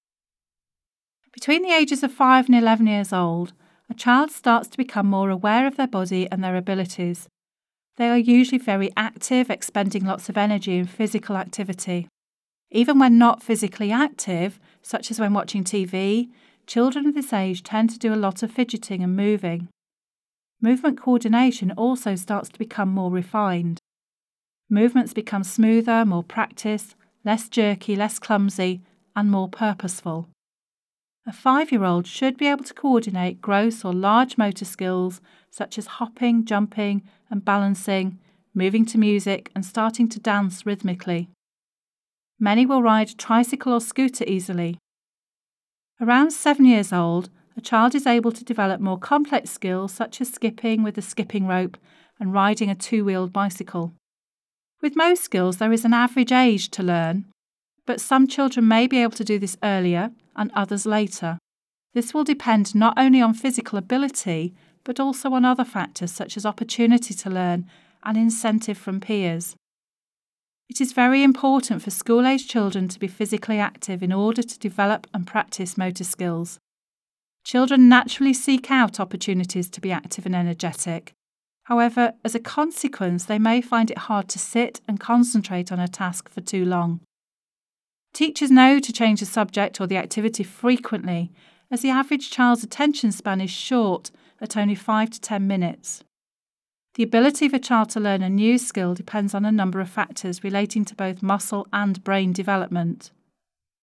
Narration audio (OGG)